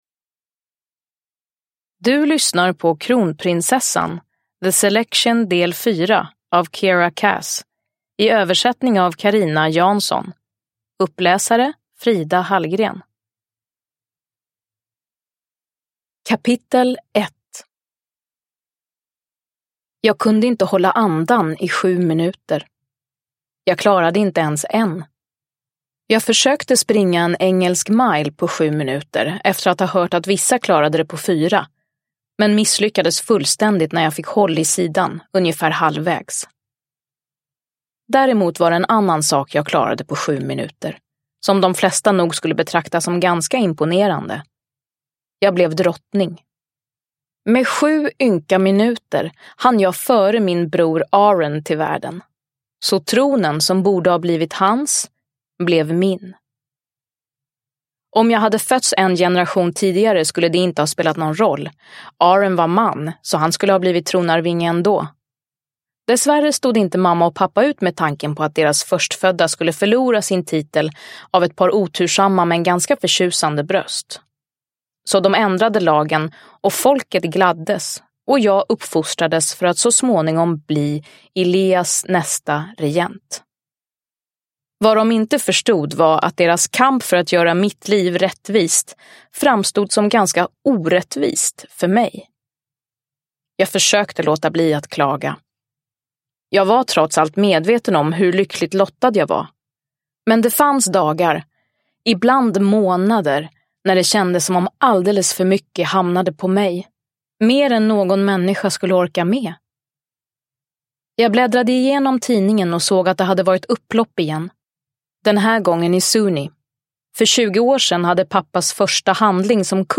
Kronprinsessan – Ljudbok – Laddas ner
Uppläsare: Frida Hallgren